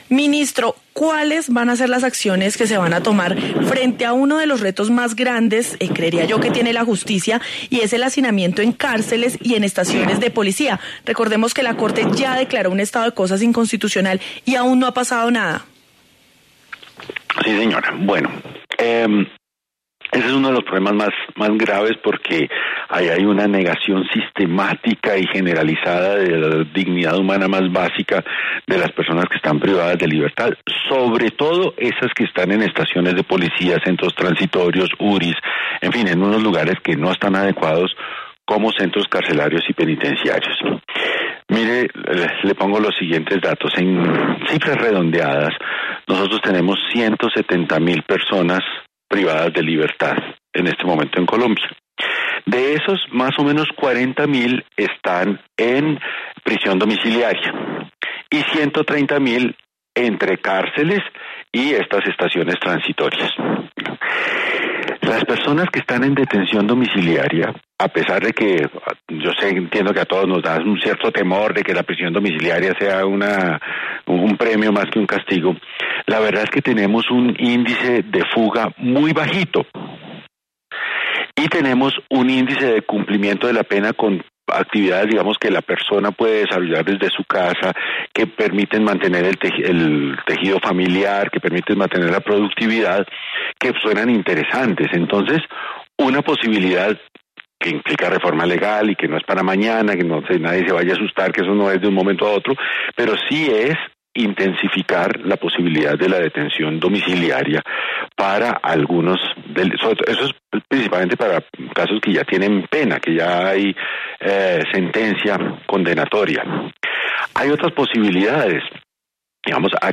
El ministro de Justicia, Néstor Osuna, habló en La W sobre uno de los mayores problemas que hay en las cárceles del país, como lo es el hacinamiento, y las estrategias que implementará para combatirlo.